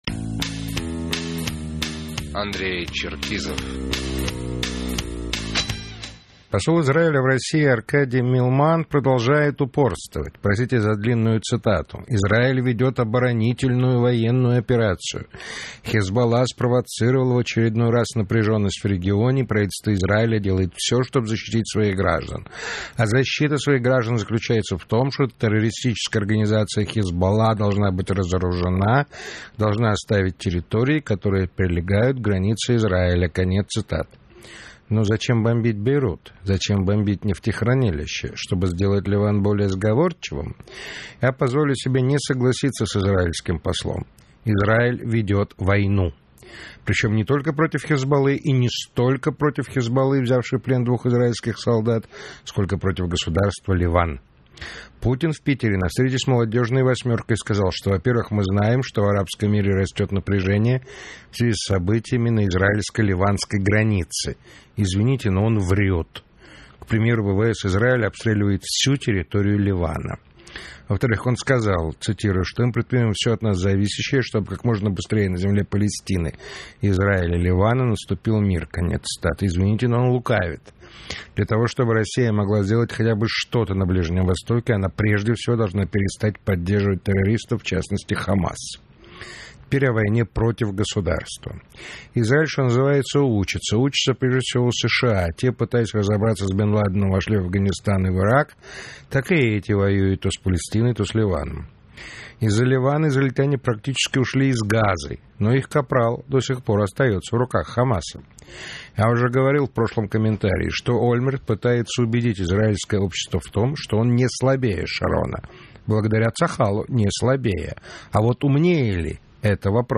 Комментарий политического обозревателя радиостанции "Эхо Москвы" Андрея Черкизова